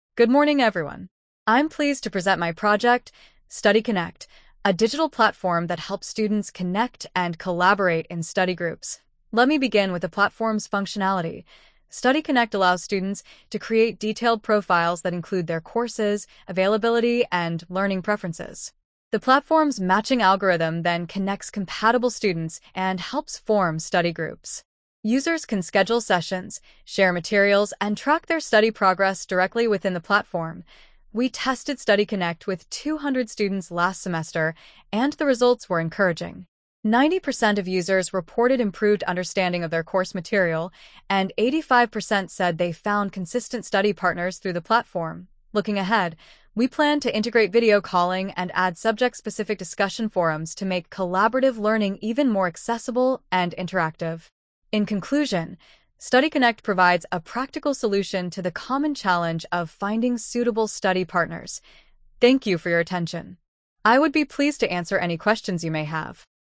Lesson 2 Share